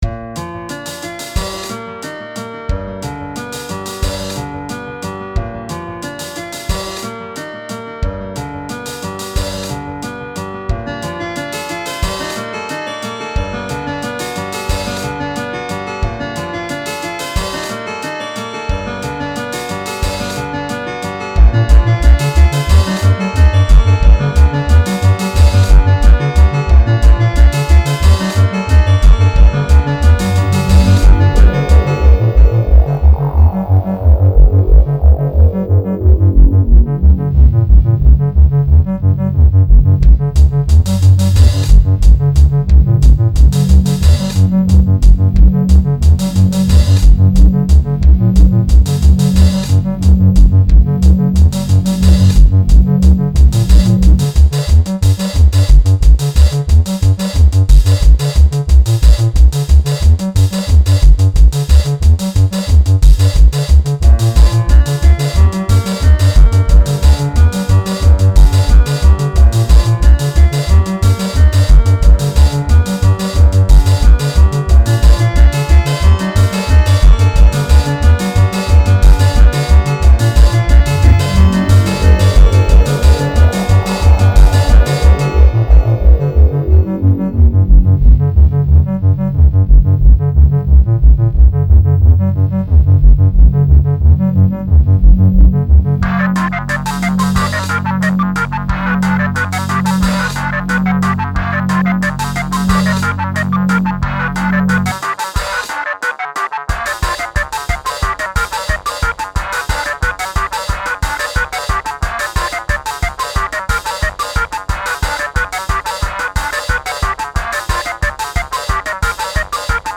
Somewhat generic technoish guitary stuff, BUT GOOD!